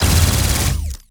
Added more sound effects.
GUNAuto_Plasmid Machinegun C Burst_02_SFRMS_SCIWPNS.wav